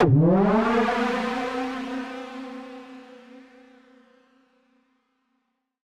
Index of /musicradar/future-rave-samples/Siren-Horn Type Hits/Ramp Up
FR_SirHornD[up]-C.wav